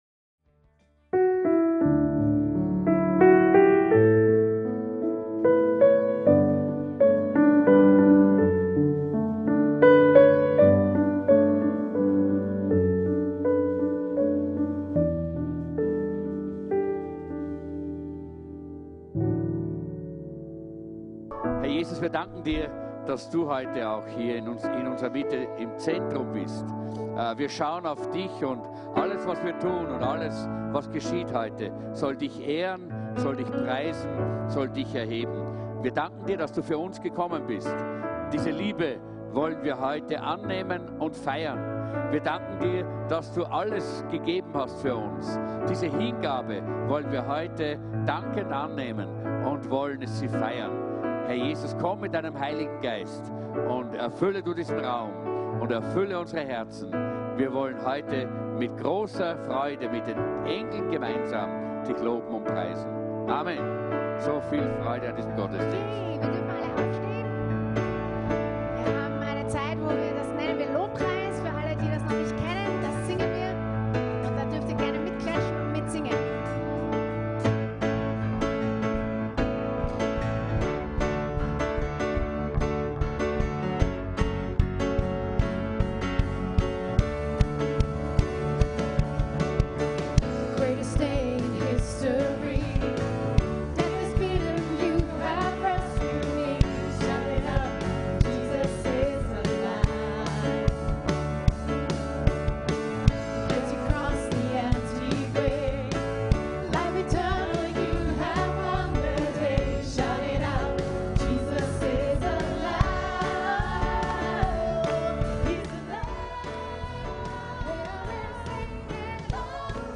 WEIHNACHTSGOTTESDIENST